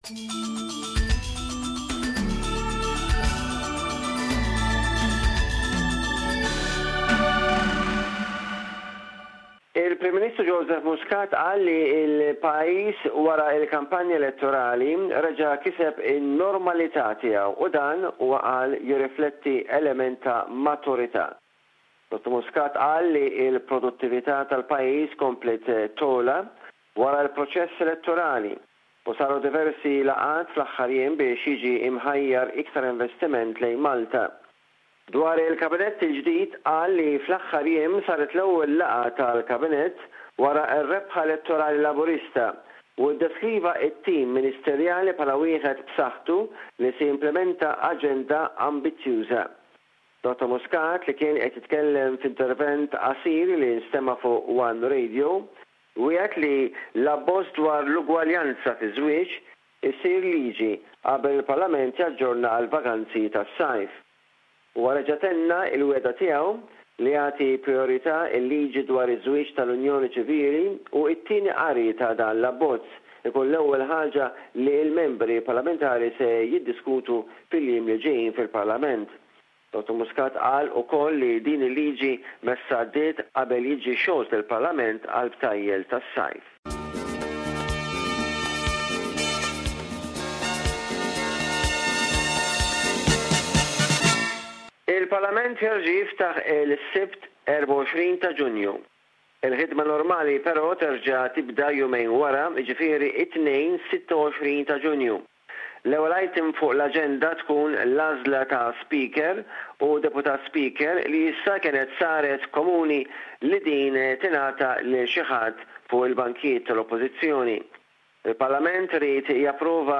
reports on the political news from Malta this week